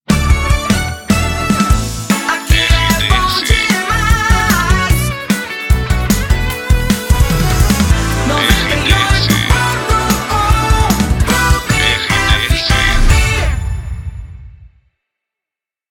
Entrada de Bloco